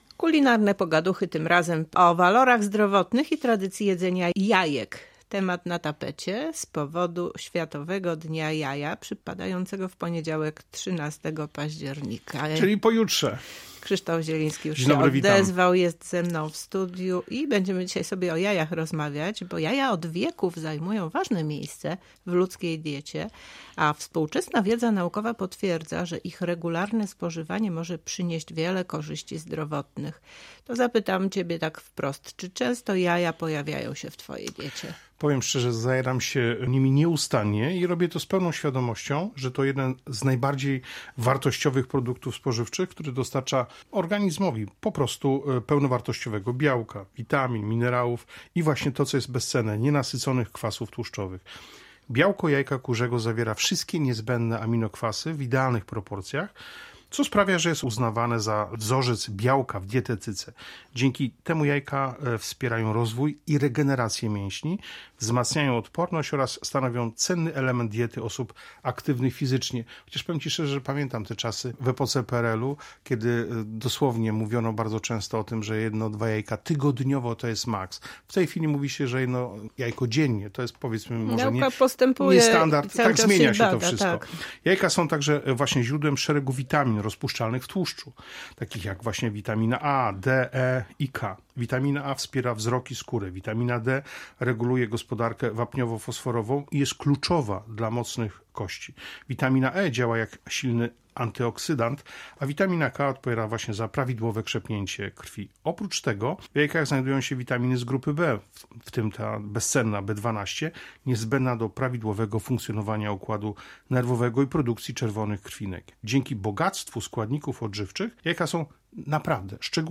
O historii jajek w kuchni, o ich walorach, a także sposobach spożywania naszej audycji „Kulinarne pogaduchy” rozmawiamy